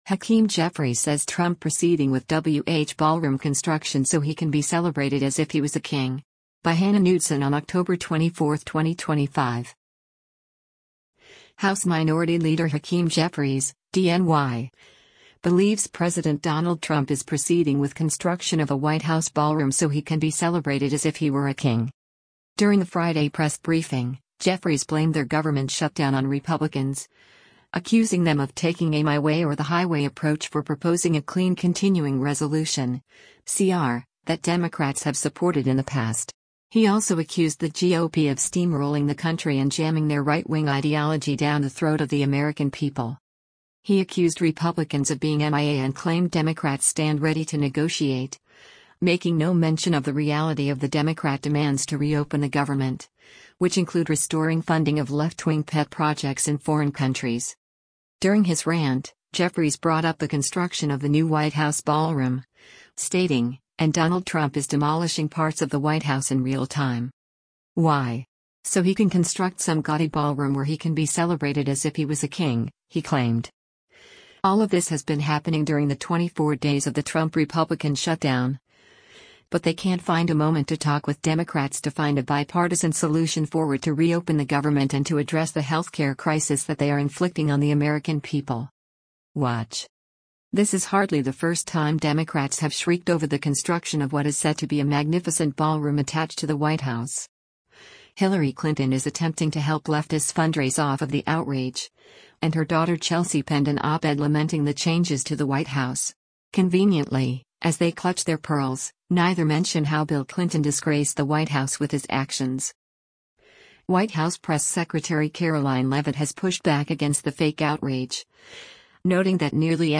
During a Friday press briefing, Jeffries blamed their government shutdown on Republicans, accusing them of taking a “my way or the highway approach” for proposing a clean continuing resolution (CR) that Democrats have supported in the past.